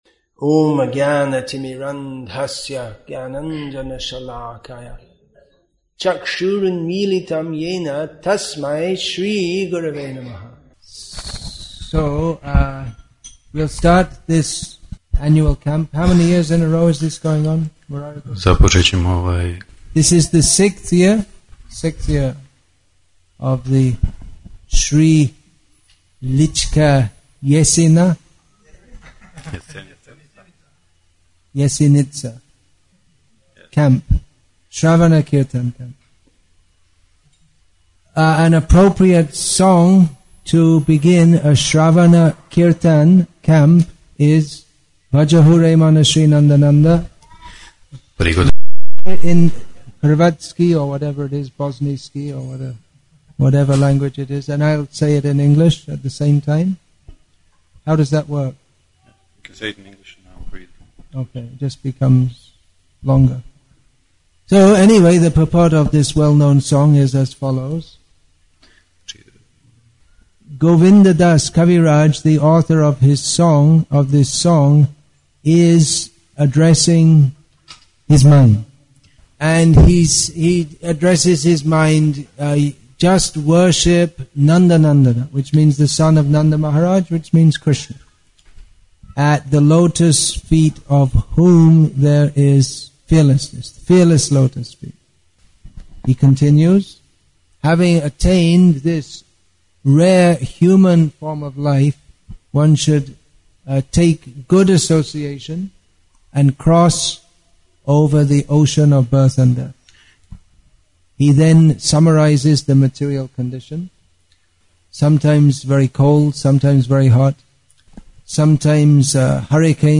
Introductory Speech